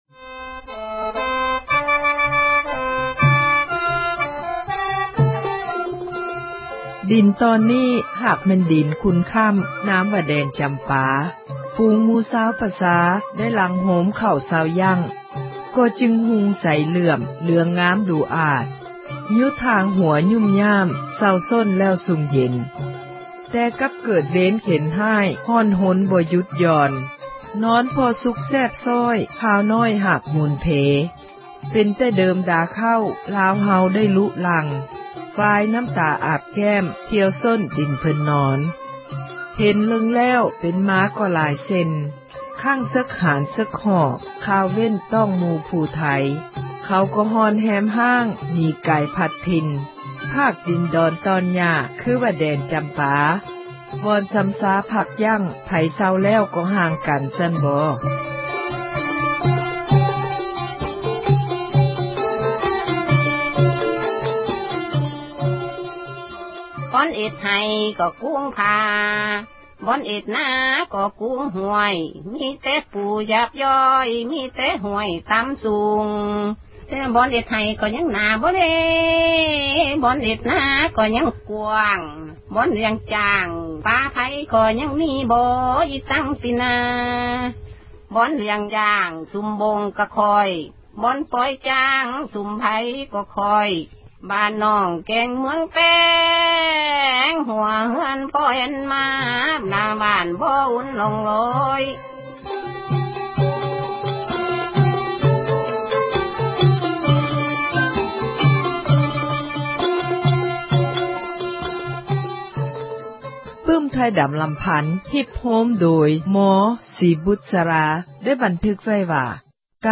ສາຣະຄະດີ ເຣື້ອງ ”ໄທດຳ ທີ່ເມືອງ ຊຽງຄານ”ຕອນທີ 3 ໃນມື້ນີ້ ຈະໄດ້ກ່າວ ເຖິງການ ອົພຍົບ ຫລາຍຄັ້ງ ຫລາຍຫົນ ຂອງ ຊາວໄທດຳ ໃນສັຕວັດ ທີ 18 ແລະ 19.